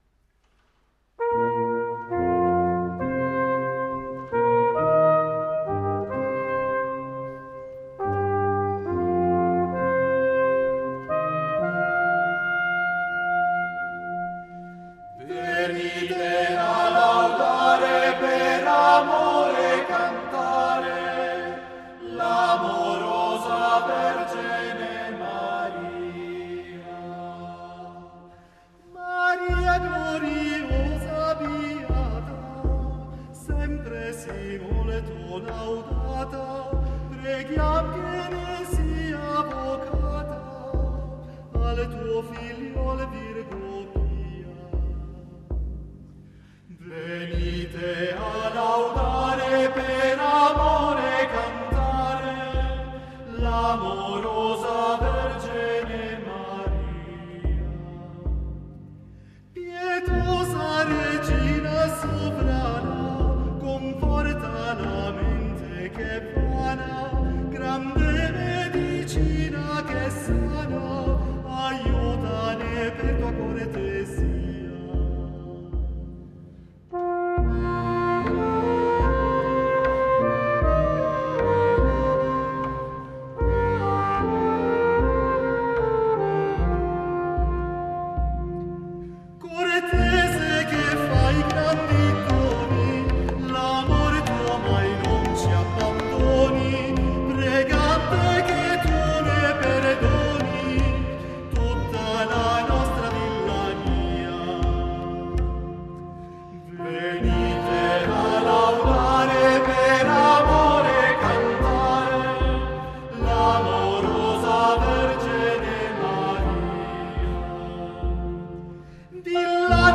Venite a laudare (rielaborazione moderna con gruppo strumentale)